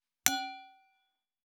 319,ウイスキー,コップ,食器,テーブル,チーン,カラン,キン,コーン,チリリン,カチン,チャリーン,クラン,カチャン,クリン,
コップ